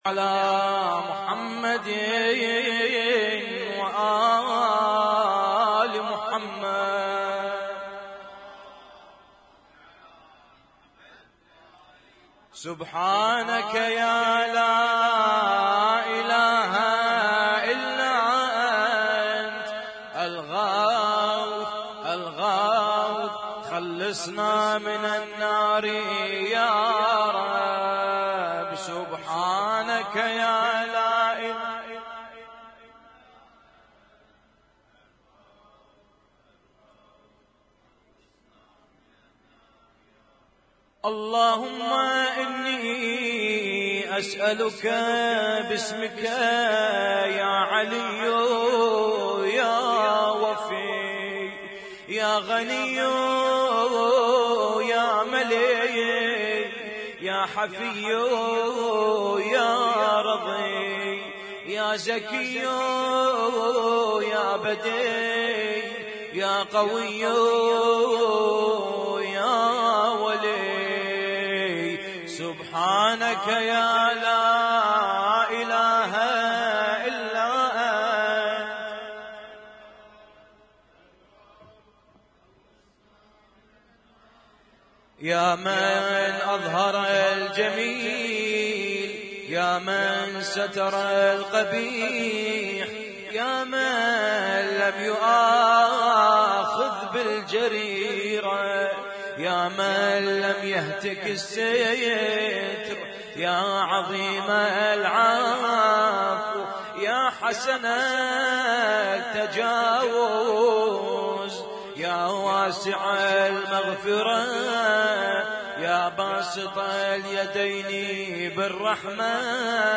إحياء ليلة 20 من شهر رمضان 1439
اسم التصنيف: المـكتبة الصــوتيه >> الادعية >> ادعية ليالي القدر